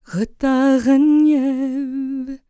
L  MOURN C02.wav